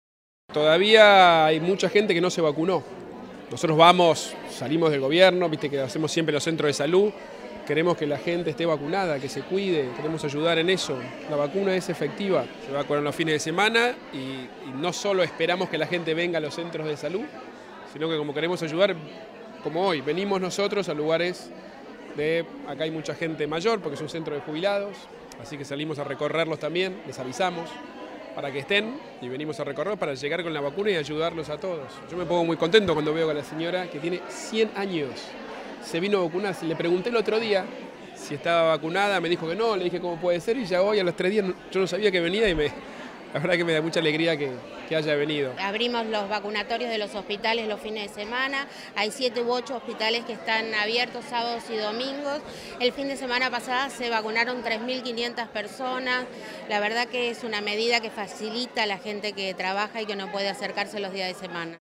El jefe de Gobierno de la Ciudad de Buenos Aires, Horacio Rodríguez Larreta, visitó hoy el Centro de Jubilados “Juan de Dios Filiberto”, ubicado en el barrio de La Boca, en el marco de la campaña de Vacunación Antigripal 2016.